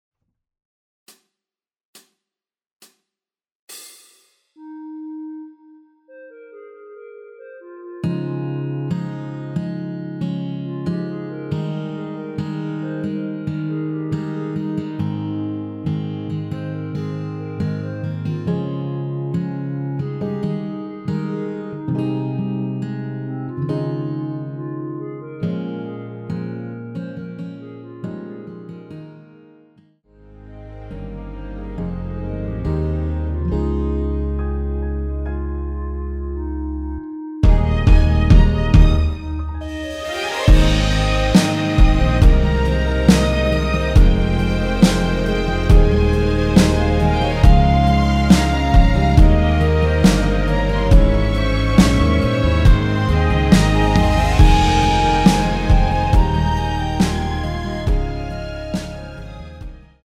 전주 없이 시작하는 곡이라서 시작 카운트 만들어놓았습니다.(미리듣기 확인)
원키(1절앞+후렴)으로 진행되는 멜로디 포함된 MR입니다.
Ab
앞부분30초, 뒷부분30초씩 편집해서 올려 드리고 있습니다.
중간에 음이 끈어지고 다시 나오는 이유는